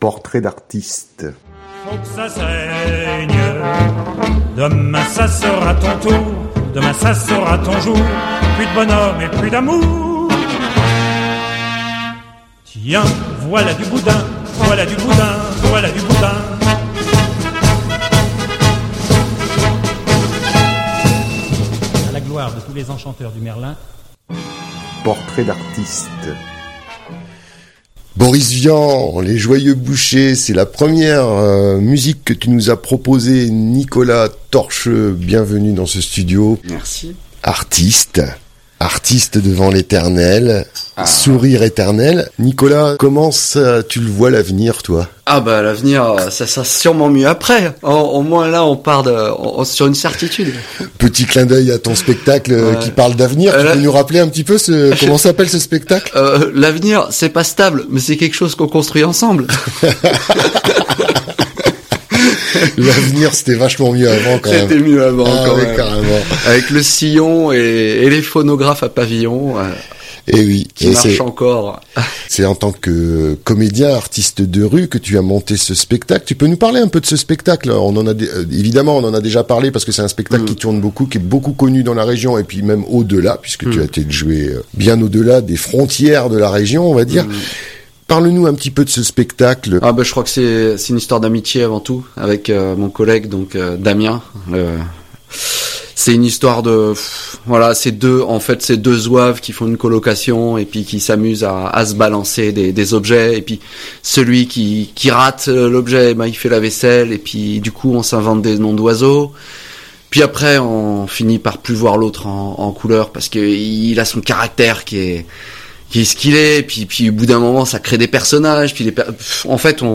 Il est venu se confier et partager sa vision du monde avec nous en parlant de ses deux projets artistiques.